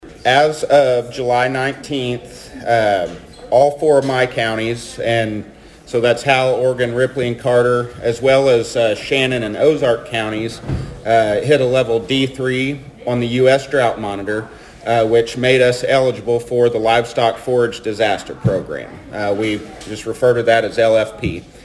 The Thayer and Mammoth Spring Rotary met Wednesday for their weekly meeting.